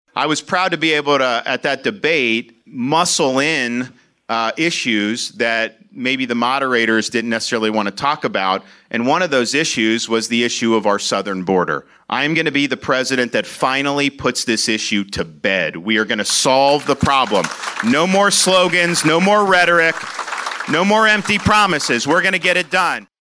DeSantis spoke to a crowd in Rock Rapids on Friday morning.